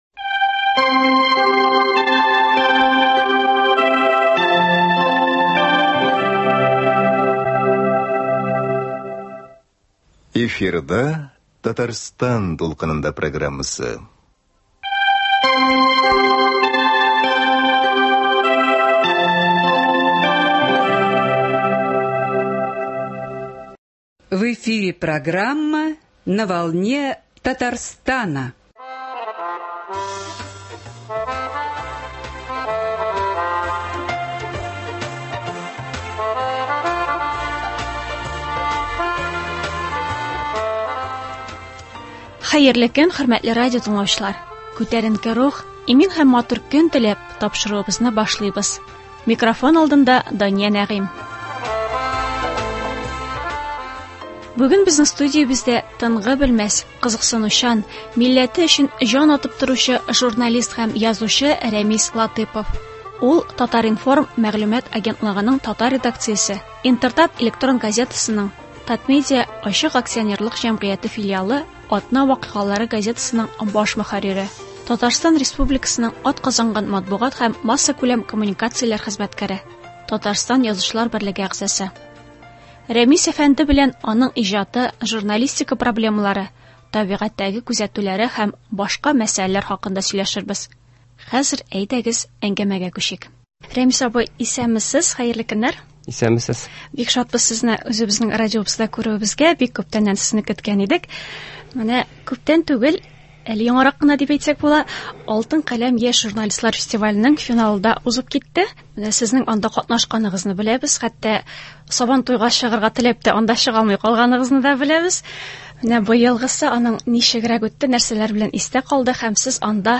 Бүген безнең студиябездә